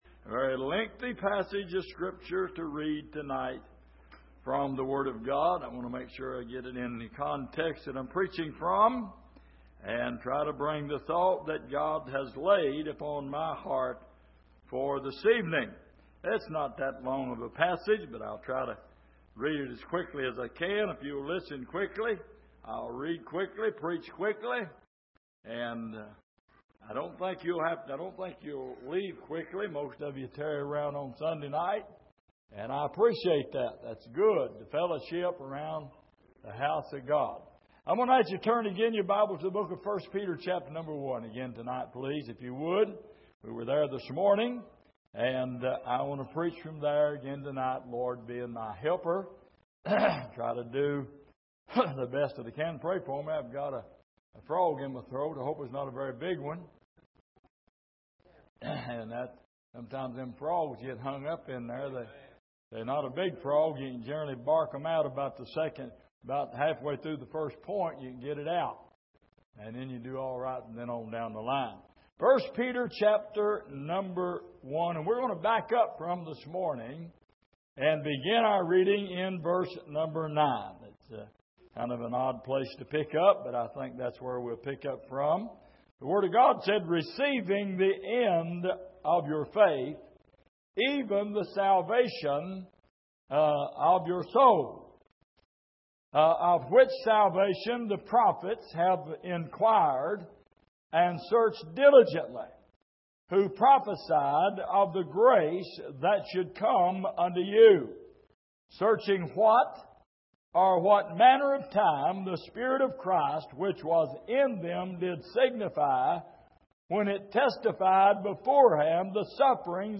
Peter's Life & I Peter Passage: 1 Peter 1:9-21 Service: Sunday Evening A String Of Pearls « The Blessing Of Redemption Our Full Joy